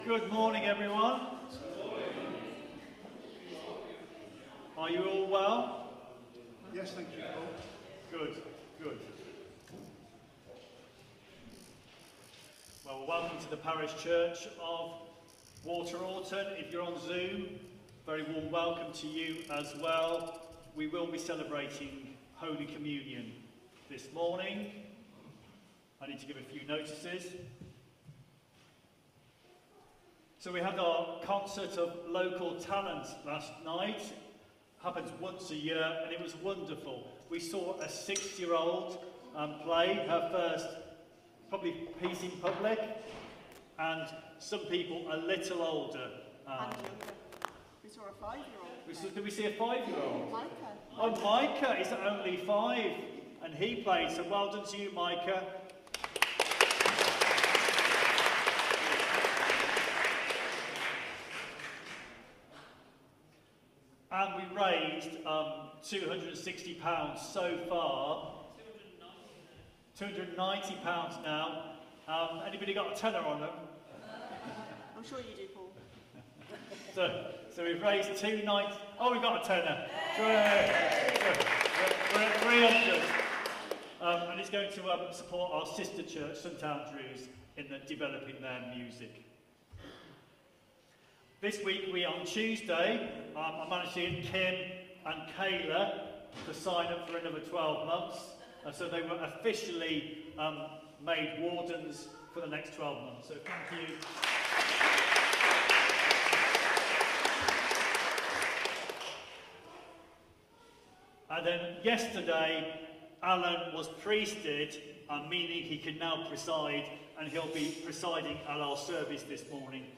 Media for Contemporary Worship on Sun 06th Jul 2025 11:00 Speaker